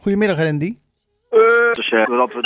Tijdens de wekelijkse editie van Zwaardvis belde we met de uit onze regio afkomstige folk-punk band Drunken Dolly.